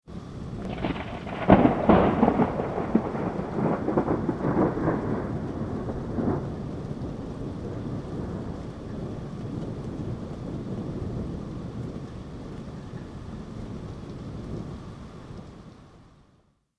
دانلود آهنگ رعدو برق 26 از افکت صوتی طبیعت و محیط
دانلود صدای رعدو برق 26 از ساعد نیوز با لینک مستقیم و کیفیت بالا
جلوه های صوتی